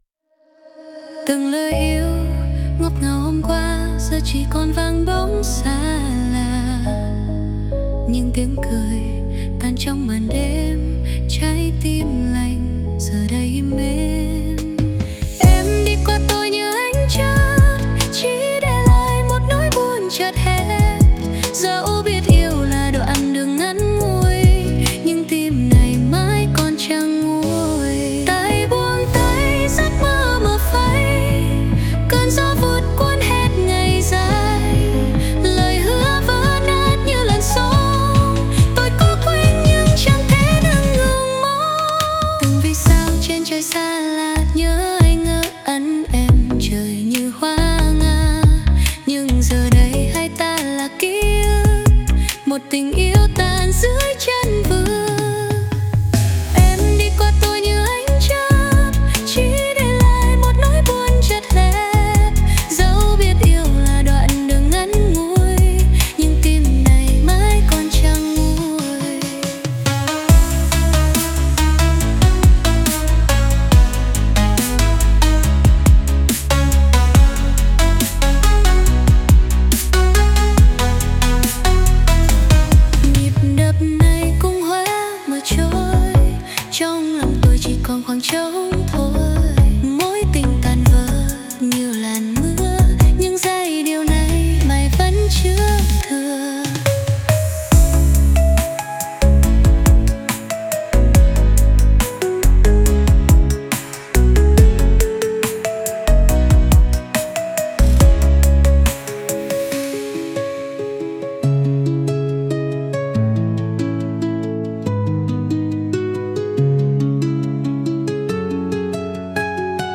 • Mô tả: “Một bài hát remix sôi động về một mối tình tan vỡ, đậm chất EDM pha chút buồn.”
• Phong cách nhạc (Style of Music): Chọn “EDM” hoặc “Dance Remix” để tạo không khí năng động.